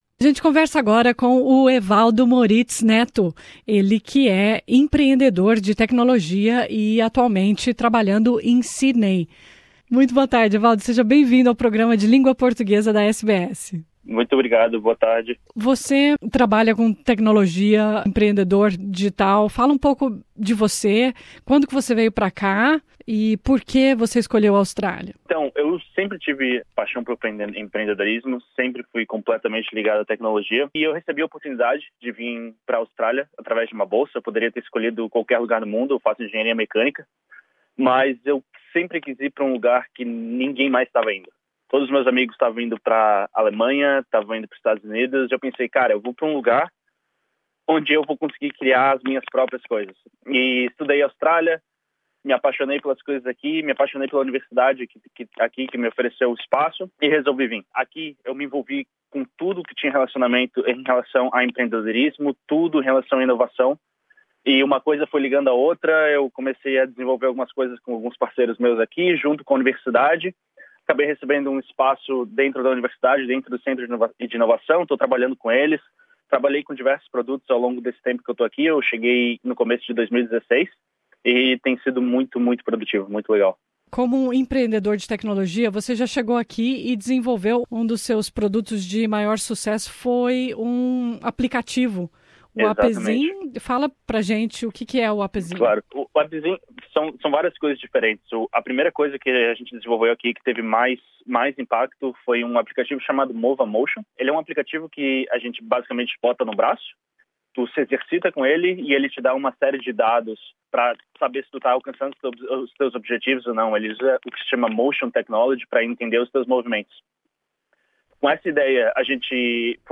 Nessa entrevista ele fala sobre o seu processo de desenvolvimento de produtos, da criação a execução, como ele identifica oportunidades e o que os startups devem fazer em primeiro lugar antes de começar qualquer projeto.